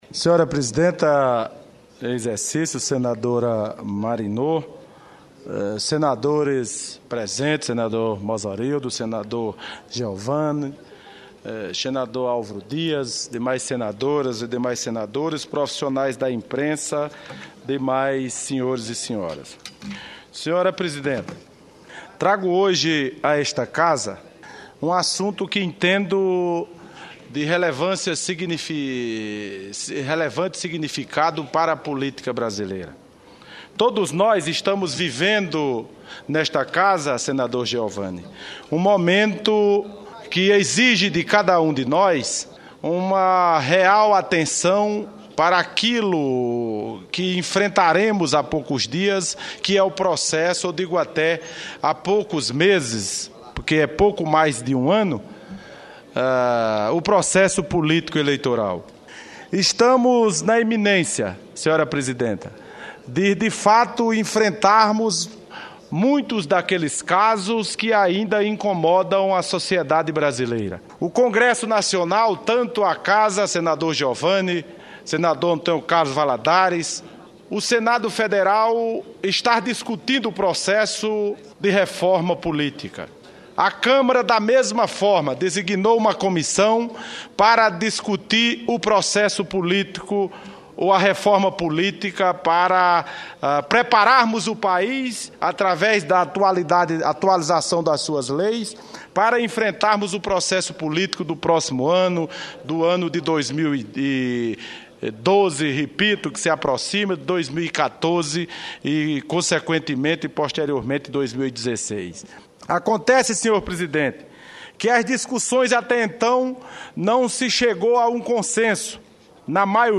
Em discurso no Plenário, o senador Wilson Santiago (Bloco/PMDB-PB) ressaltou o processo de Reforma Política que está em debate no Congresso Nacional. Santiago pediu que seja reavaliada a Proposta de Emenda à Constituição de nº 73, para que, a partir de 2016, sejam afastados do cargo, seis meses antes da realização das eleições, aqueles candidatos que disputam a reeleição.